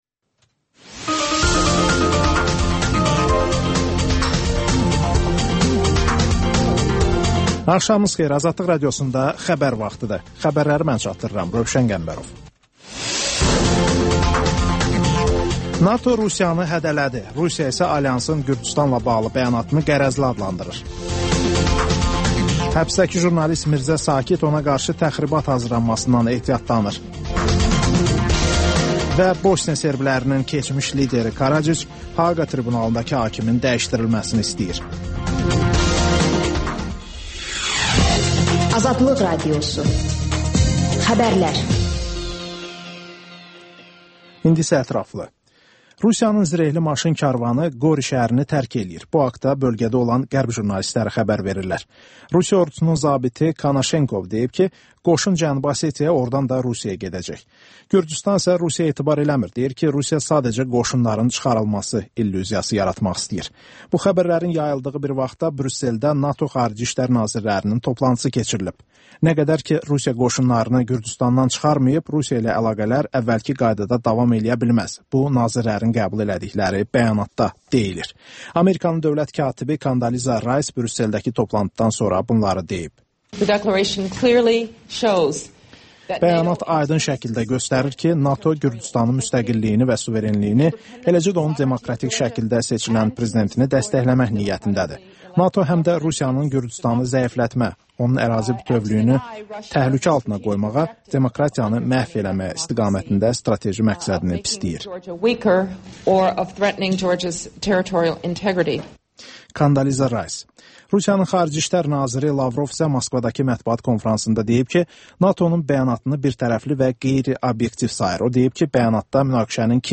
Xəbərlər, müsahibələr, hadisələrin müzakirəsi, təhlillər, sonda ŞƏFFAFLIQ: Korrupsiya haqqında xüsusi veriliş